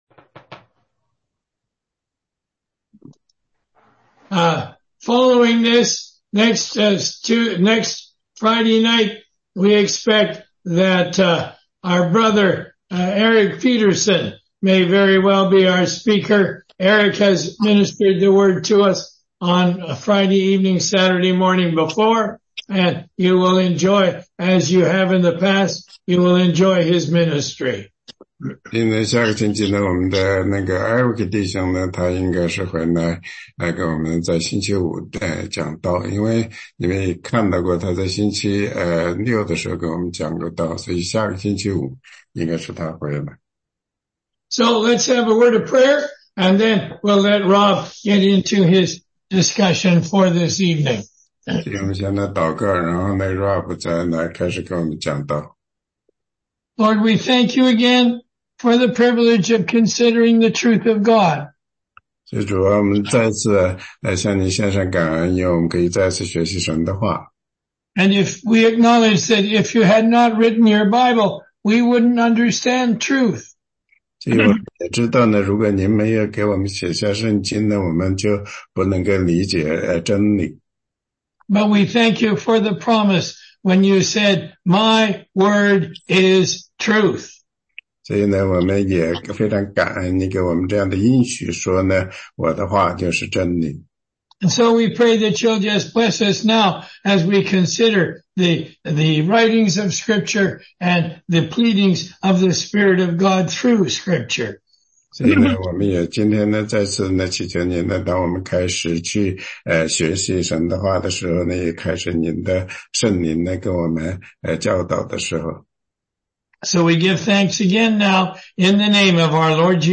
16街讲道录音
中英文查经